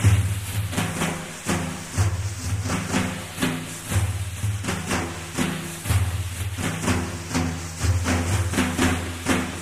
persian-drums.mp3